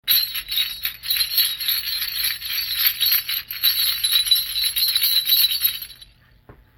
Small Brass Bells
Beautiful brass, clam style embossed jingle bells.